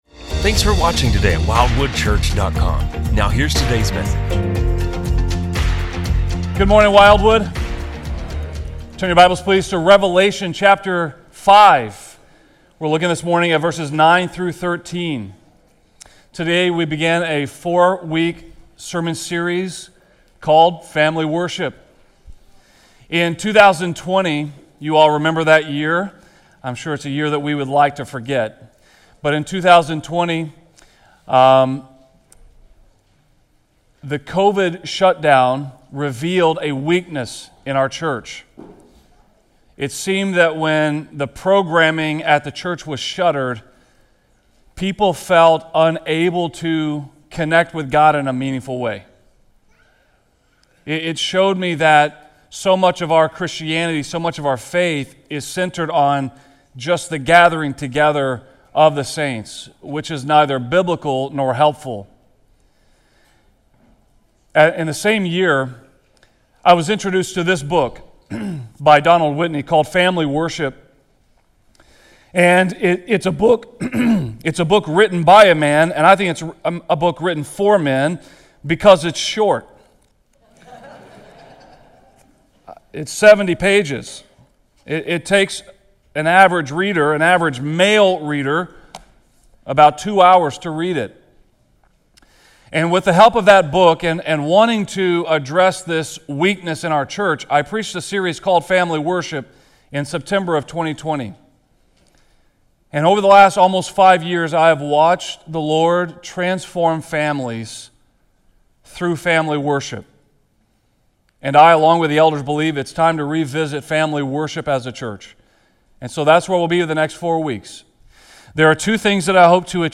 This week we launched a four-week series on Family Worship, calling believers to restore daily worship in the home as a vital act of devotion. Rooted in Revelation 5, this sermon proclaims that God is worthy of continual praise—not just in church, but around our kitchen tables. With both inspiration and practical equipping, it challenges fathers in particular to embrace their sacred role as spiritual leaders.